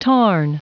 Prononciation du mot tarn en anglais (fichier audio)
Prononciation du mot : tarn